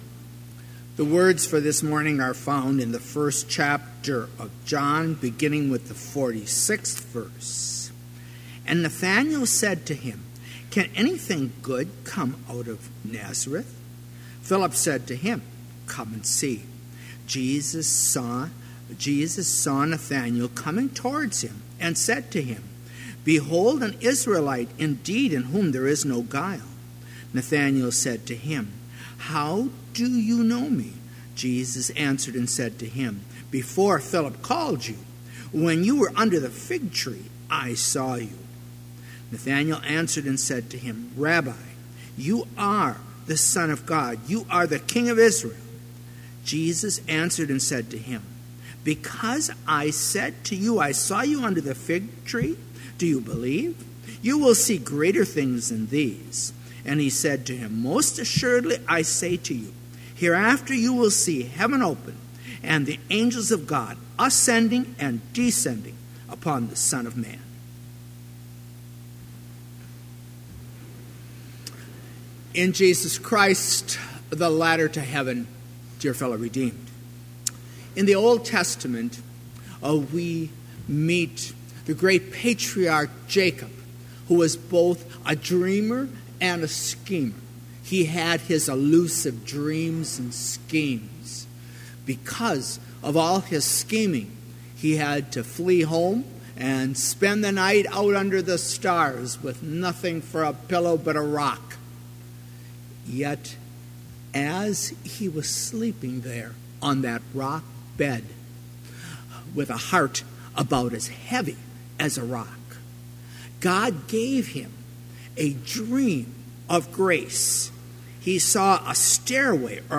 Chapel worship service held on January 31, 2018, BLC Trinity Chapel, Mankato, Minnesota, (video and audio available)
Complete service audio for Chapel - January 31, 2018